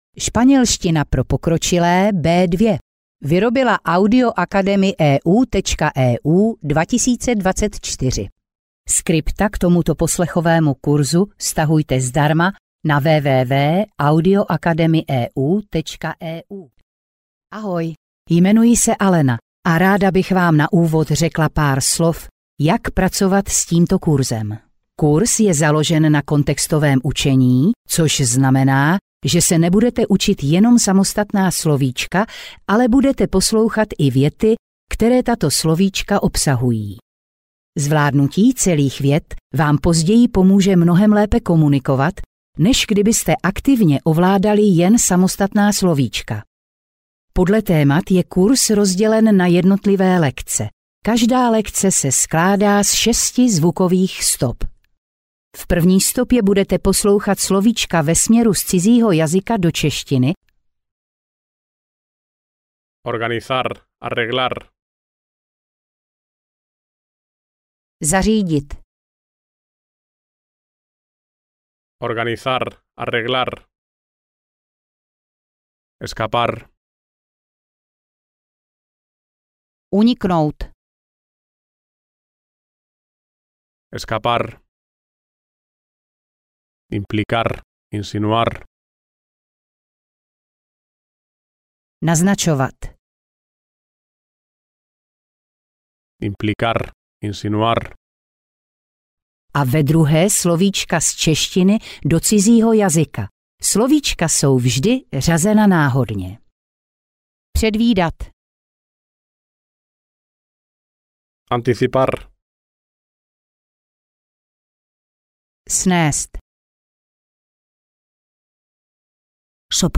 Španělština pro stredne pokročilé B2 audiokniha